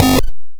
Quick Bass 003.wav